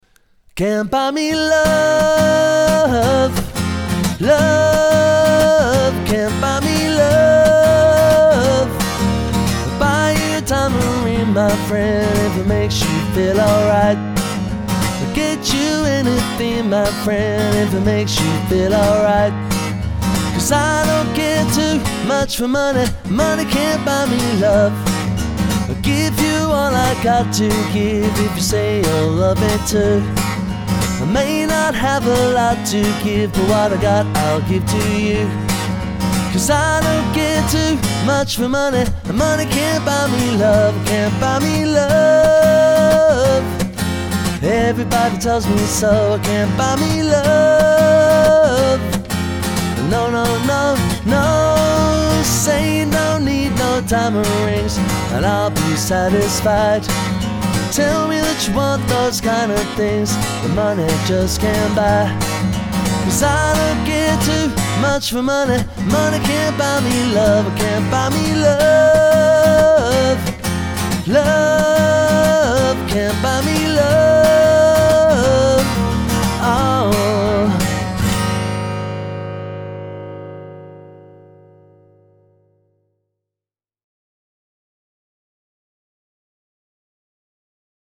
With just a guitar and his voice
a full sound that is easy to listen to